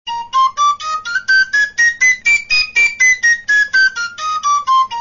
LA FLAUTA MARAGATA
Estas notas, comenzando por el LA bajo, son las que definen con bastante precisión el que denominamos "modo de SOL".
picado
picadog.mp3